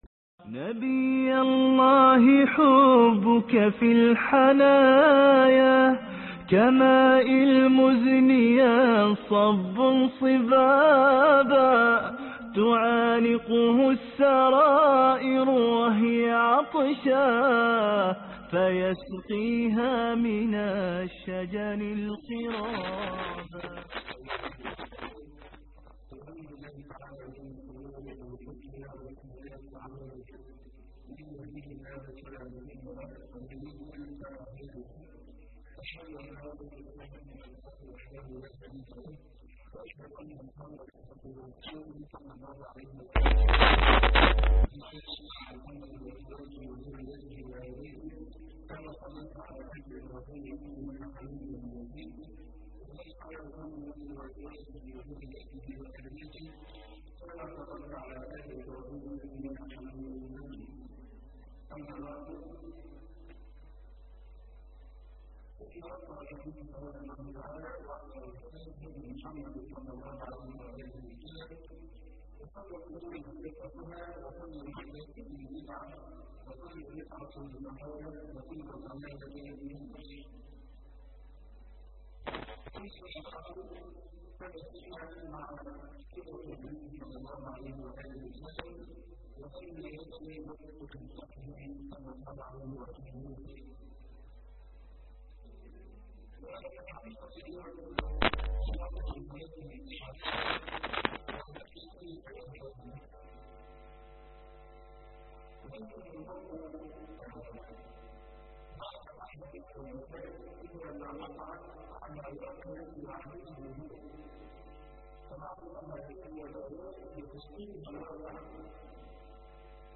الدرس3 (20صفر 1433هـ)السيرة النبوية